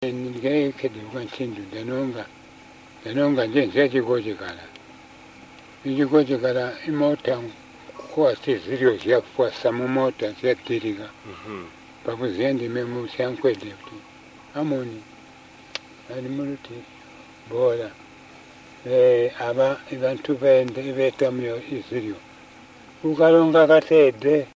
The oral interview covers an historical account of famine in Southern Part of Zambia between after 1920.